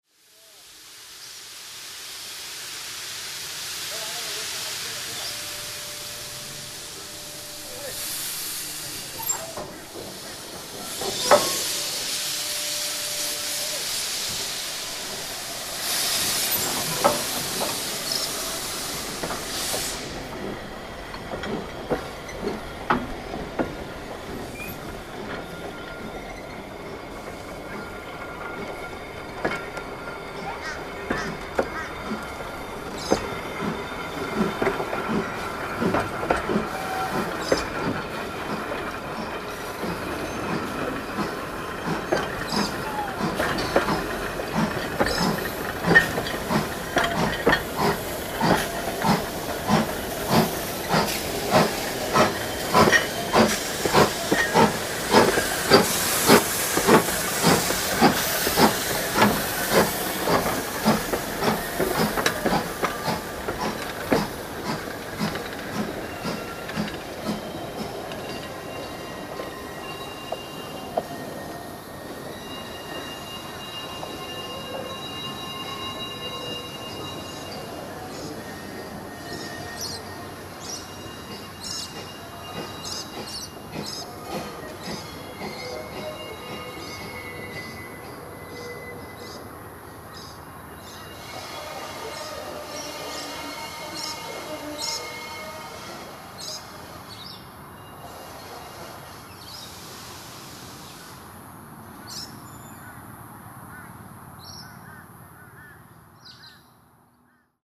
The home of Australian Steam Train Sounds (and the occasional diesel)
Accompanied by the sounds of crows and lorikeets, we hear PB15 448 pushing back her train into Box Flat yard, so that the crew can have their meal break.  It's so nice to be able to record a stephenson valve geared loco in Queensland again, being nigh on 12 years since we last heard those sounds in our state.  28 June 2009